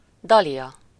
Ääntäminen
Ääntäminen Tuntematon aksentti: IPA: /ˈdɒlijɒ/ Haettu sana löytyi näillä lähdekielillä: unkari Käännöksiä ei löytynyt valitulle kohdekielelle.